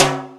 44_23_tom.wav